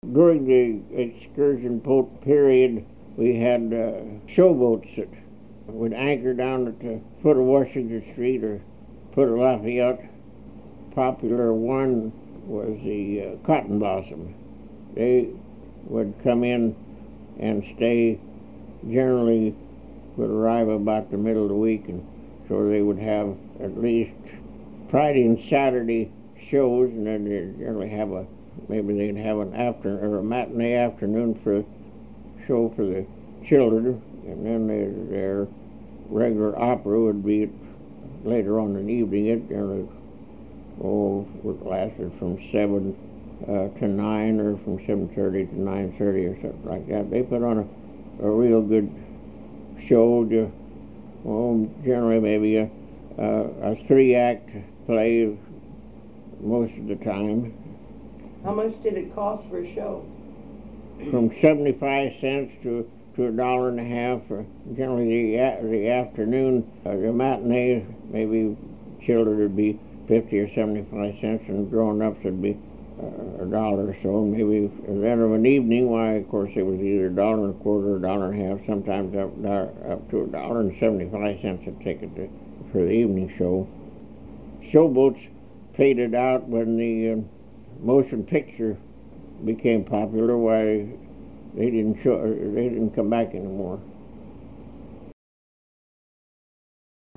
HTR Oral History, 07/23/1